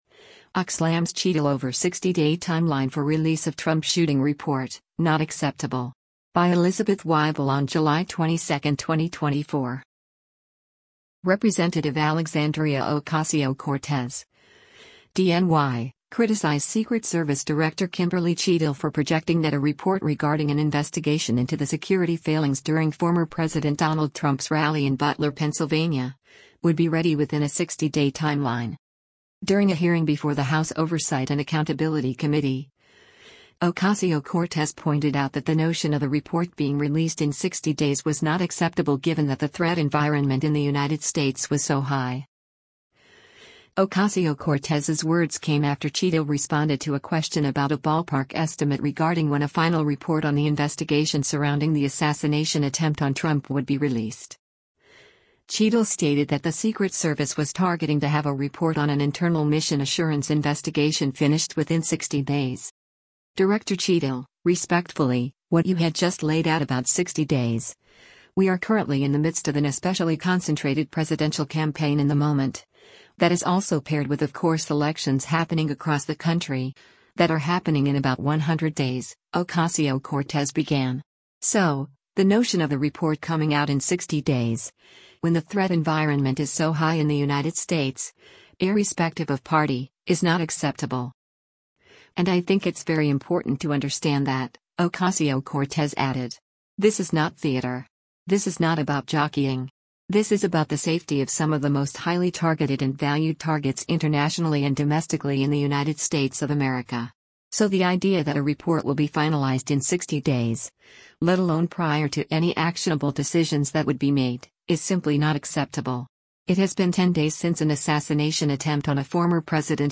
During a hearing before the House Oversight and Accountability Committee, Ocasio-Cortez pointed out that “the notion of a report” being released in 60 days was “not acceptable” given that the “threat environment” in the United States was “so high.”